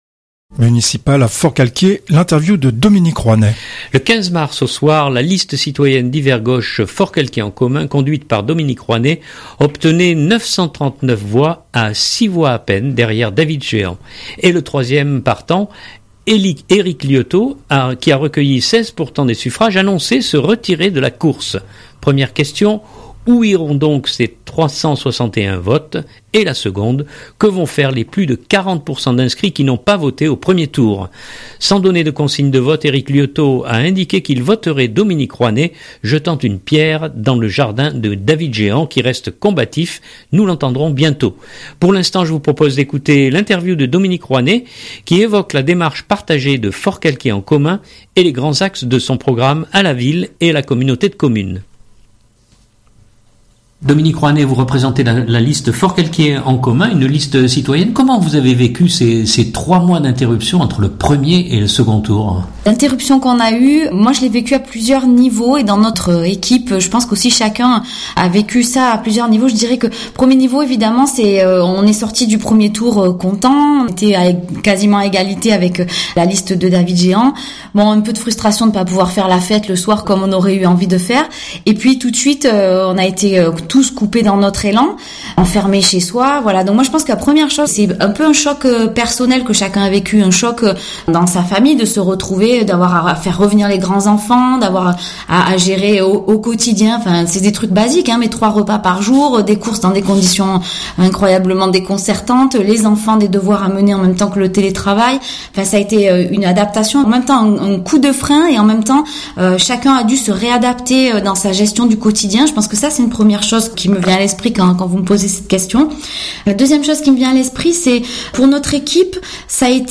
Municipales à Forcalquier : l’interview